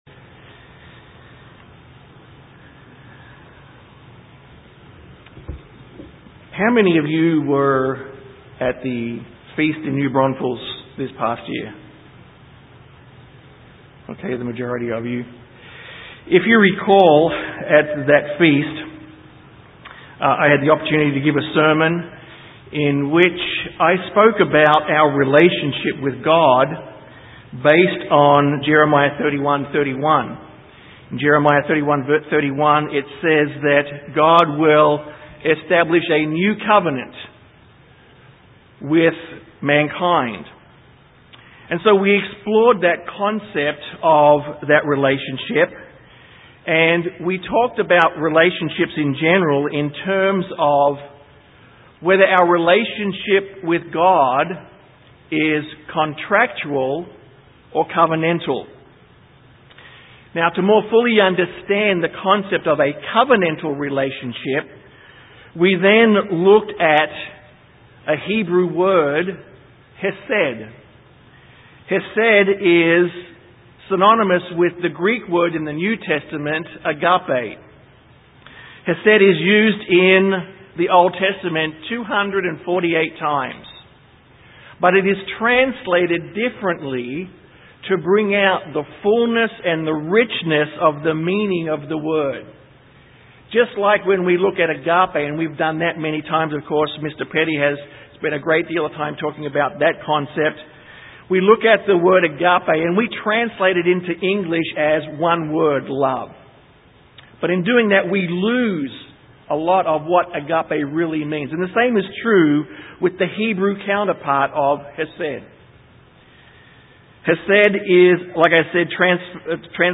Given in San Antonio, TX
UCG Sermon Studying the bible?